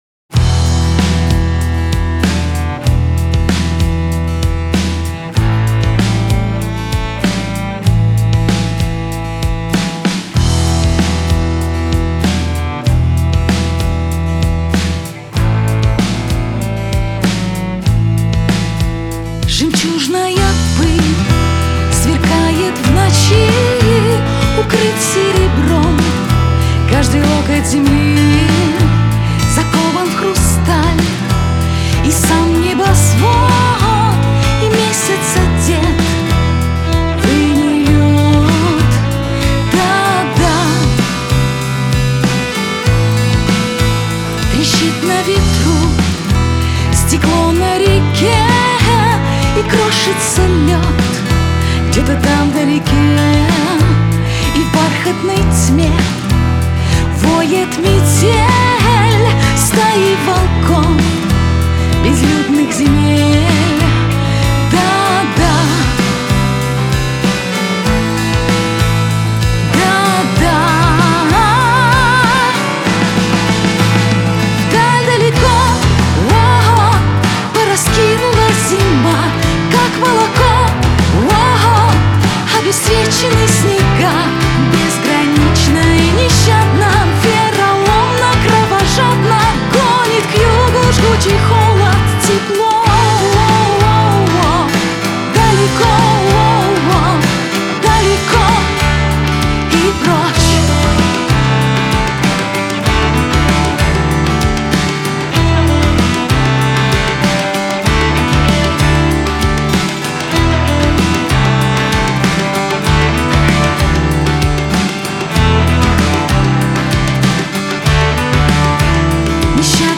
Категории: Русские песни, Рок, Поп.